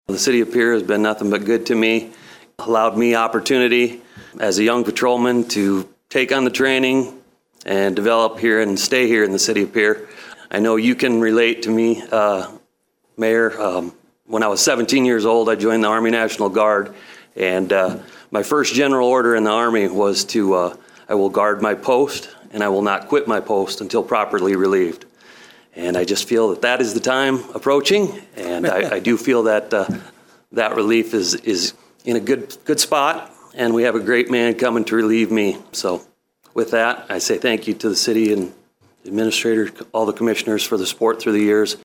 Pierre city commissioners and city officials applaud after hearing from retiring Police Chief Jason Jones.